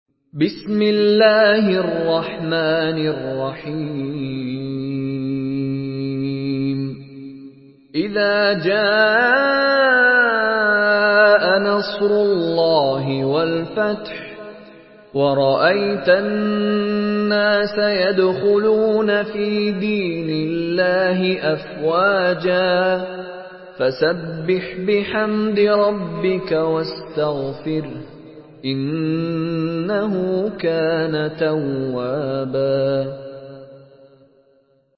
Surah Nasr MP3 in the Voice of Mishary Rashid Alafasy in Hafs Narration
Surah Nasr MP3 by Mishary Rashid Alafasy in Hafs An Asim narration.
Murattal Hafs An Asim